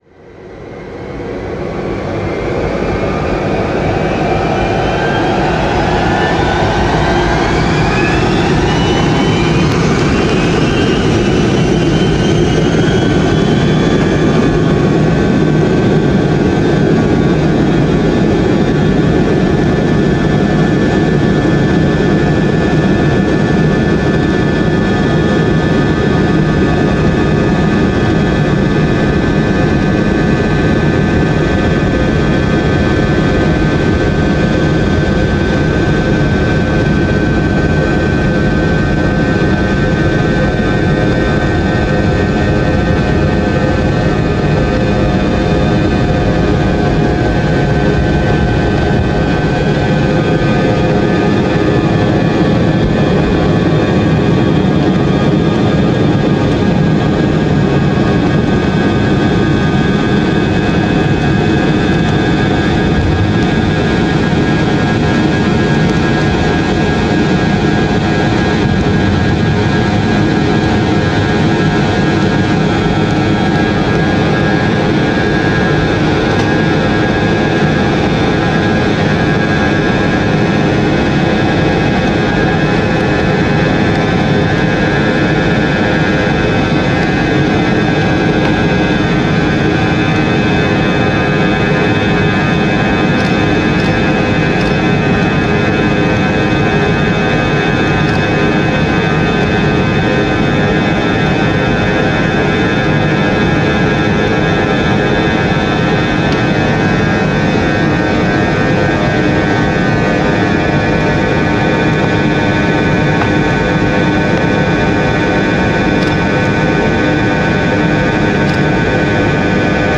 На этой странице собраны реалистичные звуки двигателя самолета: от плавного гула турбин до рева при взлете.
Прогрев двигателя самолета перед взлетом